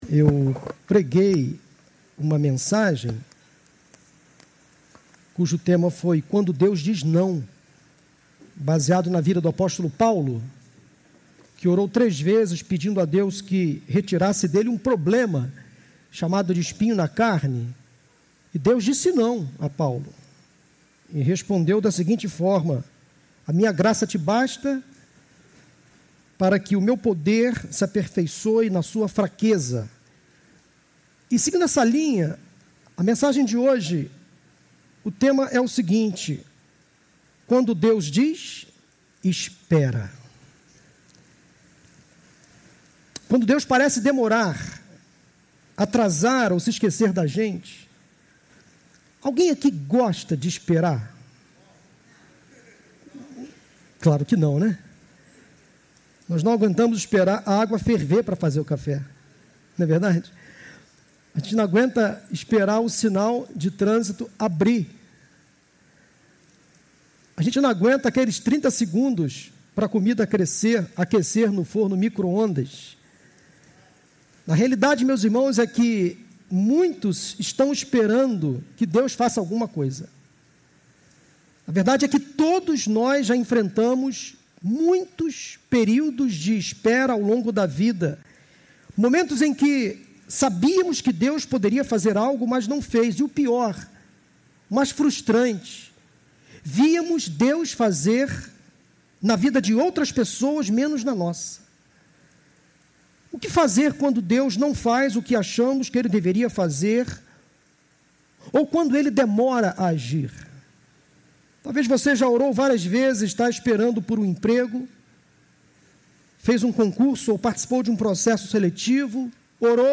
PIB Madureira